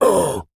Male_Grunt_Hit_20.wav